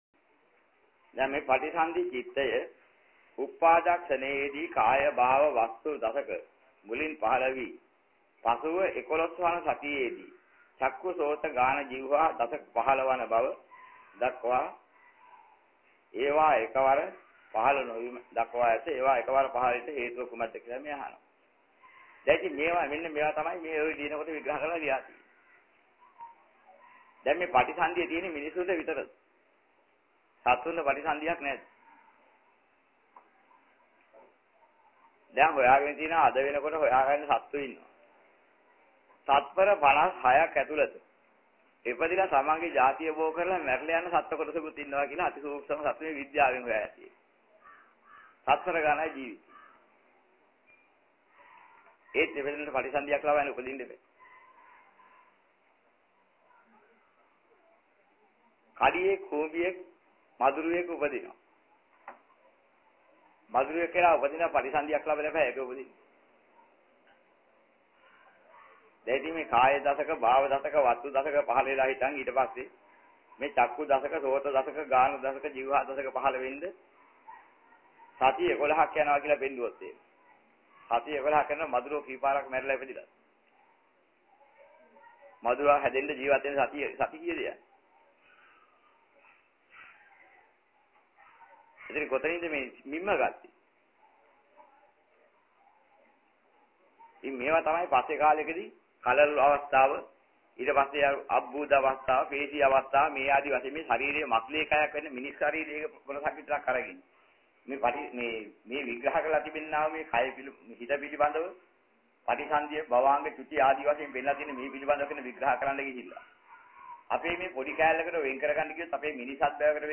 මෙම දේශනාවේ සඳහන් වන ධර්ම කරුණු: